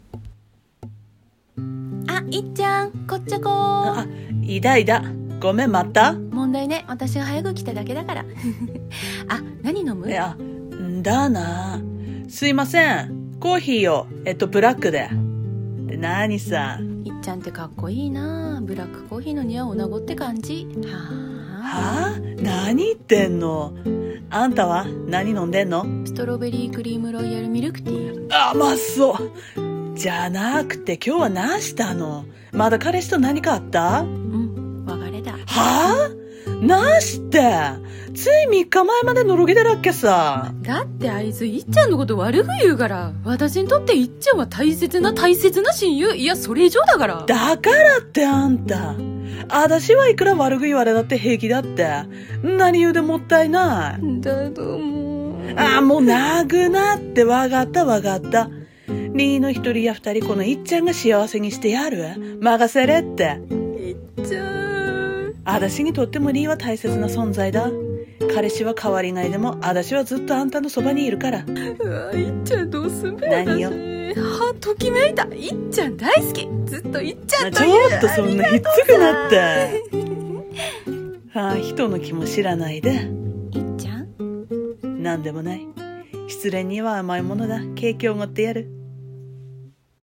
[方言Ver.]
女子2人声劇